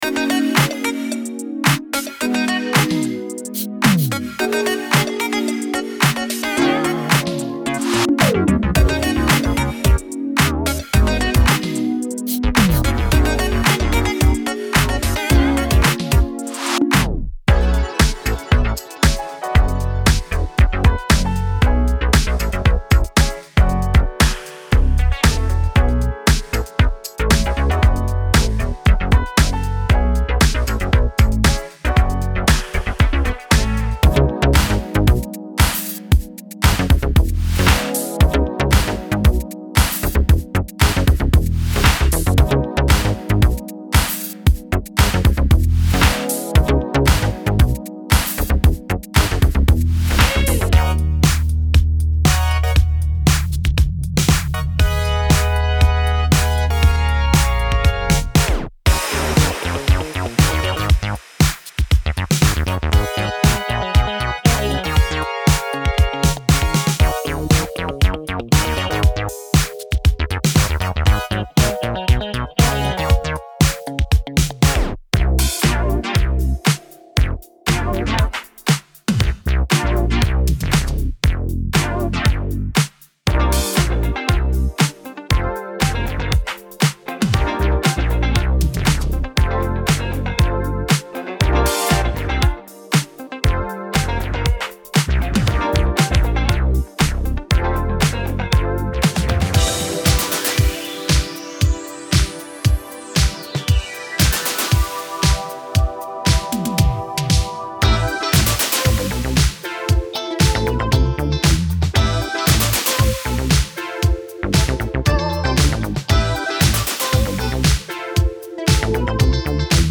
デモサウンドはコチラ↓
Genre:Nu Disco
15 Brass Loops
15 Guitar Loops